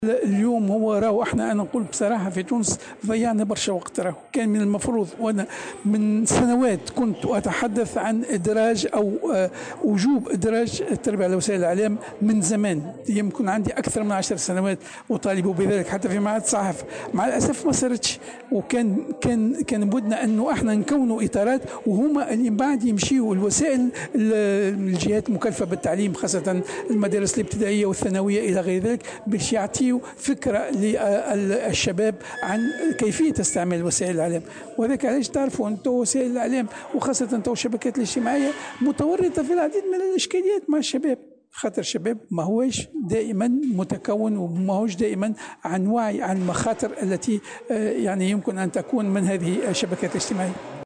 وأضاف في تصريح اليوم لمراسلة "الجوهرة أف أم" على هامش الاحتفال بالأسبوع العالمي للتربية على وسائل الاعلام، أنه لا بد من تكوين إطارات يتم إلحاقها بالمدارس الابتدائية والثانوية للتدريب على كيفية استعمال وسائل الإعلام، والتعاطي مع شبكات التواصل الاجتماعي، خاصة في ظل ما أصبحت تمثله من خطر على الأطفال والشباب.